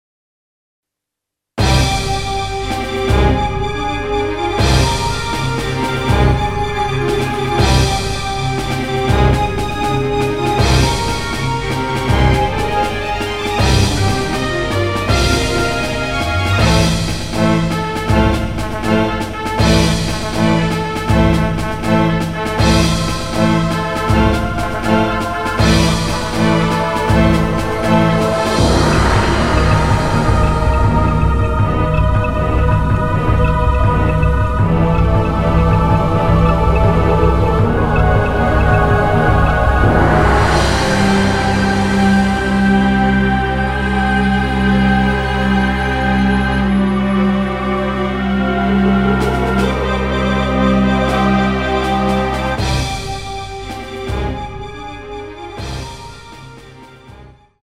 BGM试听: